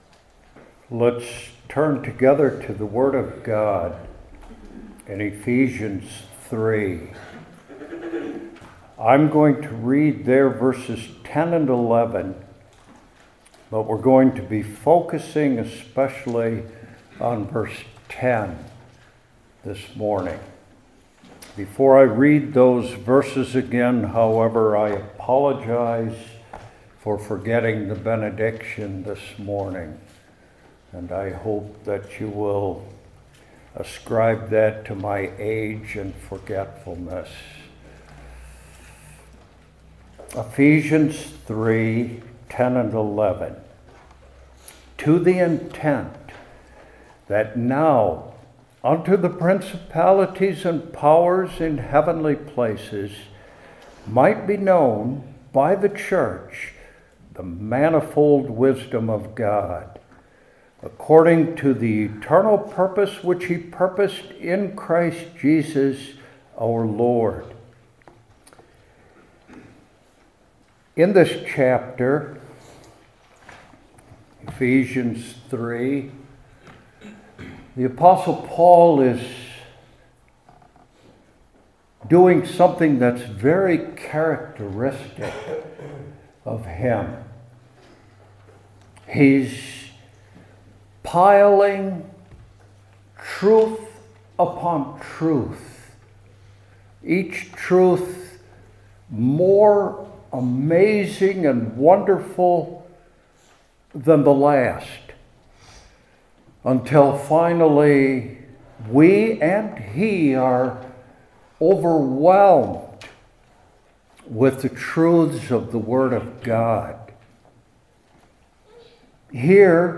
New Testament Individual Sermons I. Its Recipients II.